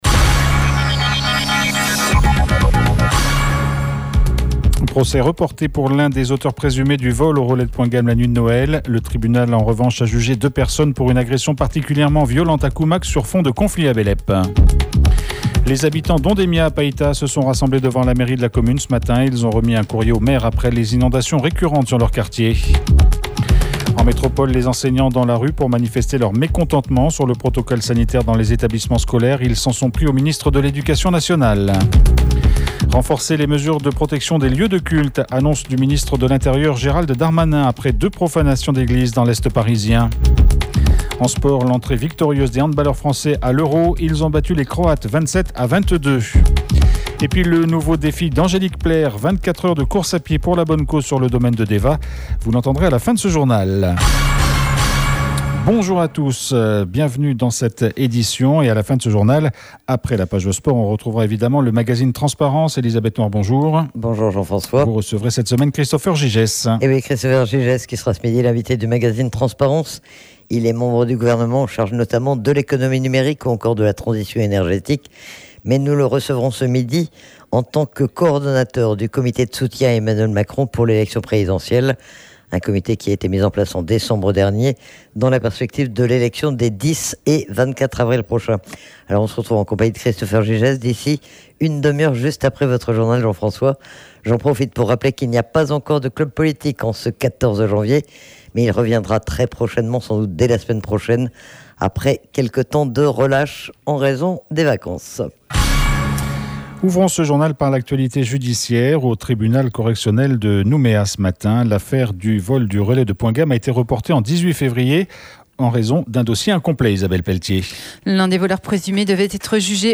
JOURNAL : VENDREDI 14/01/22 (MIDI)